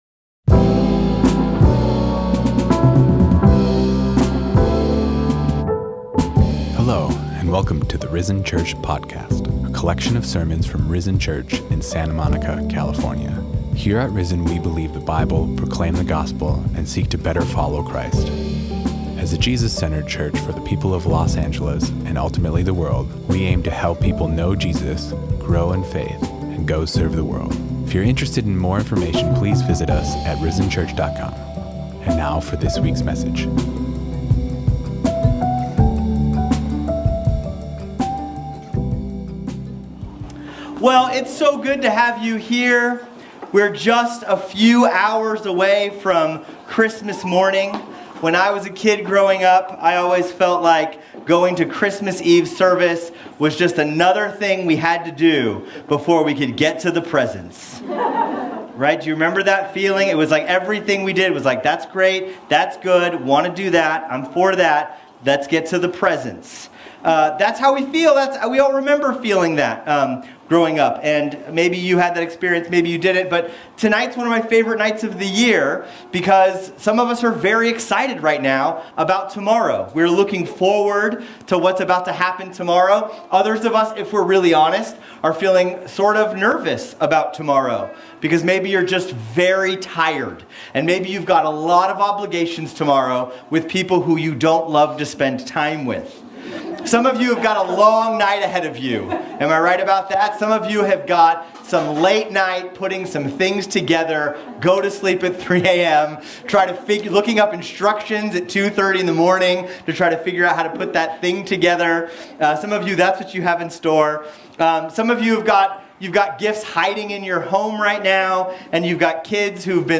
Sermons | RISEN CHURCH SANTA MONICA, INC
During our Christmas Eve service we rediscover what Christmas is actually about; the greatest gift given, Jesus Christ and His Gospel.